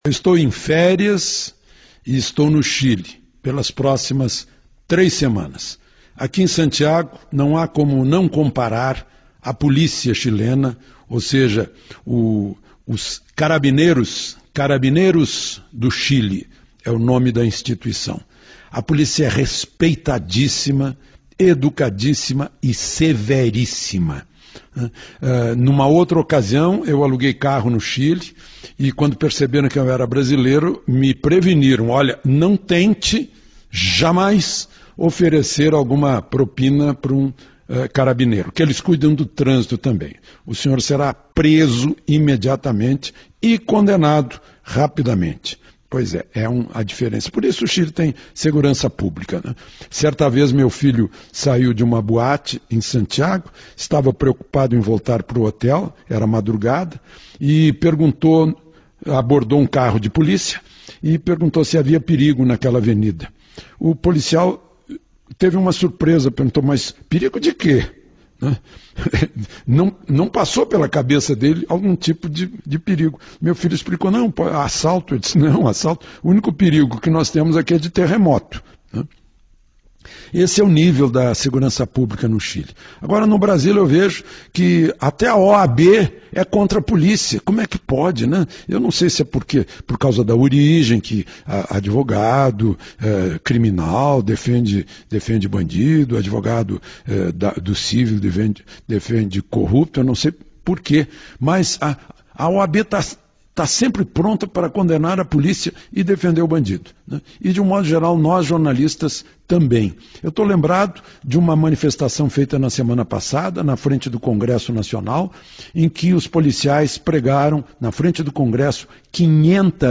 O comentário de Alexandre Garcia para a Rádio Metrópole, nesta terça-feira (3), fala da aproximação entre notícias que estão sendo veiculadas no Chile e situações que estão acontecendo no Brasil.
Ouça o comentário de Alexandre Garcia na íntegra: